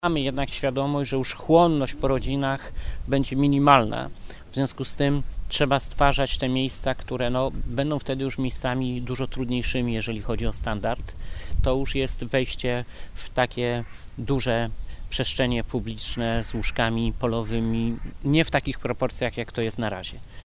– Przygotowujemy się do drugiej fali – mówi Jarosław Obremski.